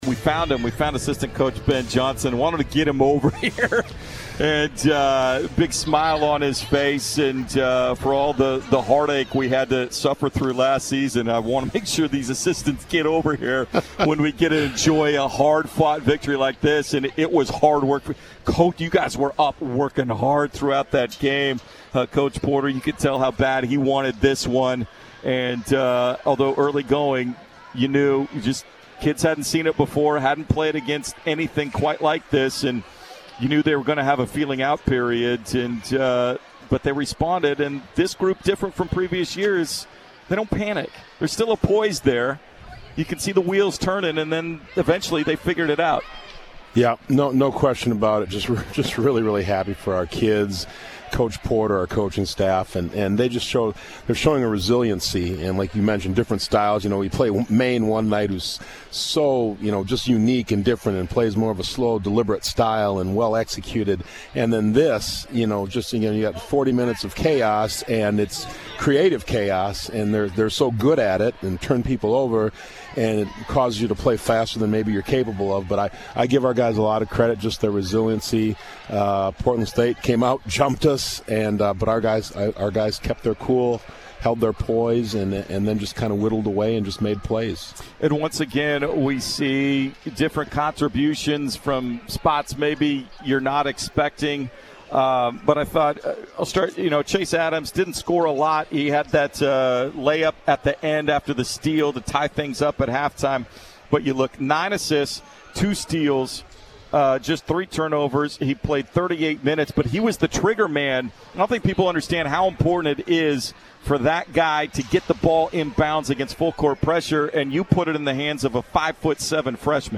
Post-Game Interview
post-game radio show on 910 ESPN-Portland to discuss the Pilots win against Portland State.